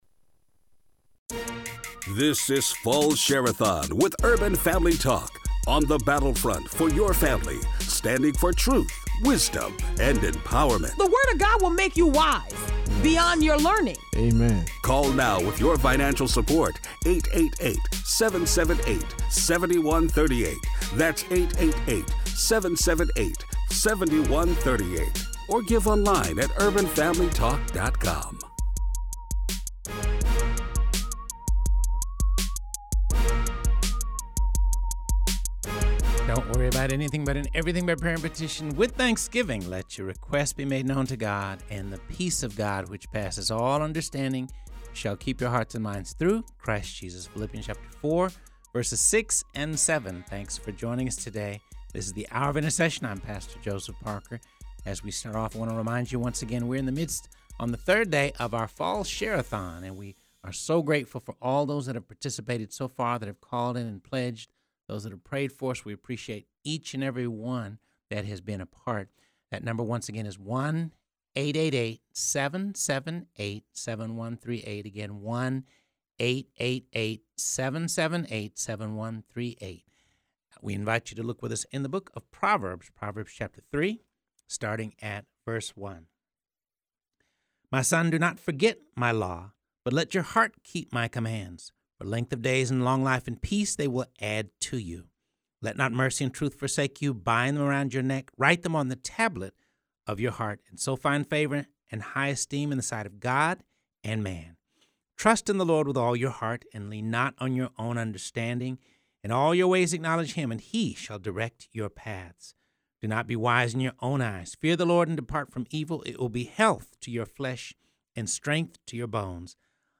in studio to talk about his walk with Christ and how that let him to Washington, D.C. and a career in radio.